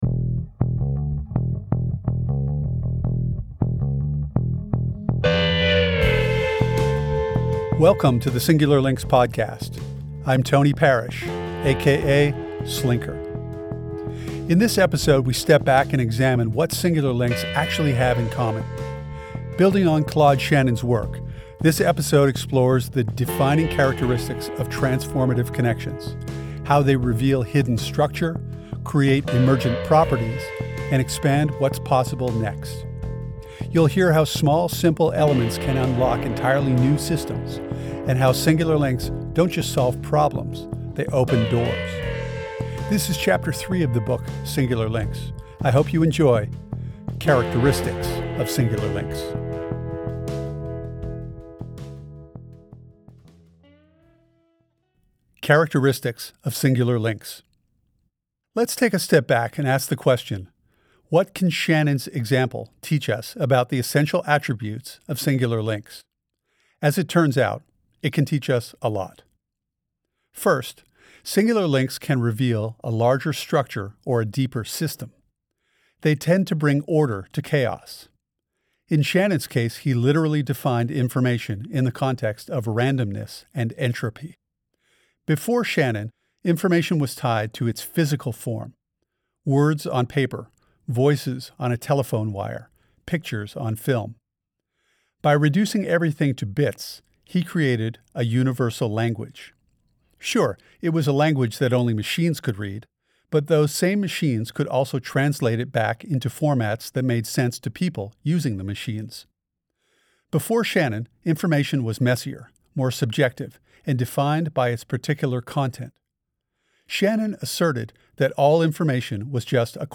This episode features Chapter Three of the audio book Singular Links.